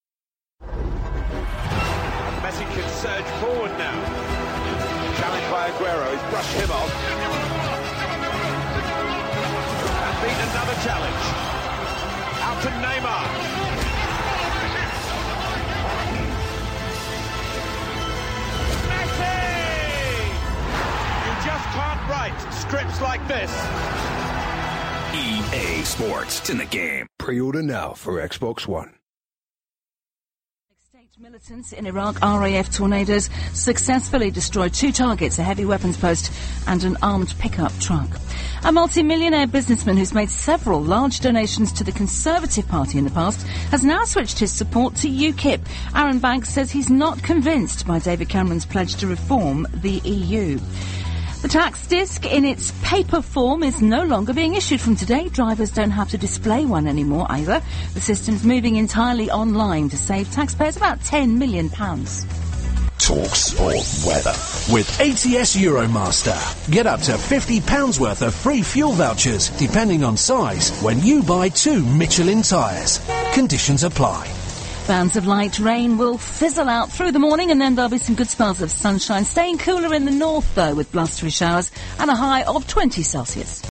UK News Bulletin (October 1) Via TalkSport